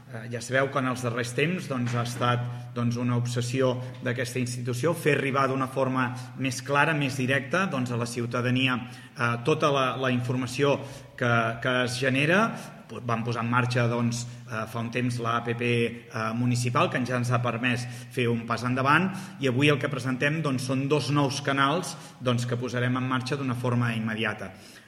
L’alcalde del municipi, Lluís Puig explica que des del consistori fa temps que treballen per millorar la comunicació amb la ciutadania.